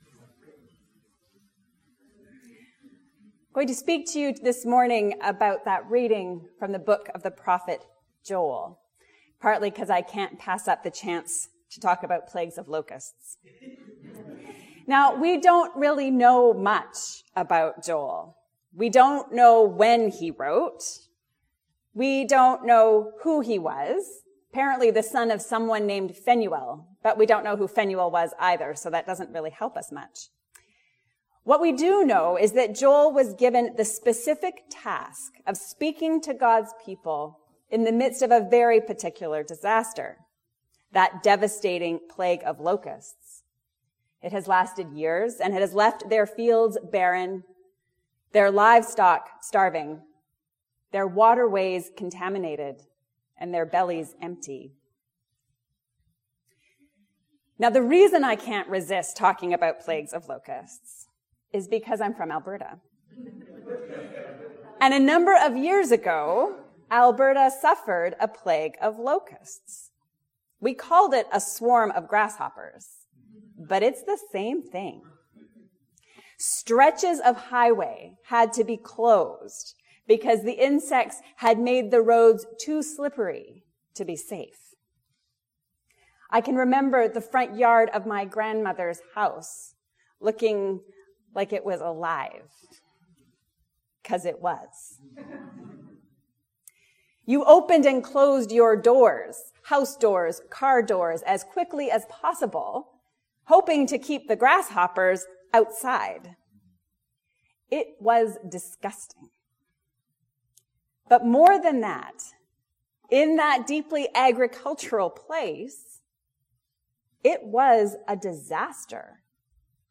The Spirit of God has been poured out on us. A sermon on Joel 2:23-32